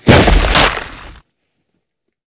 thump.ogg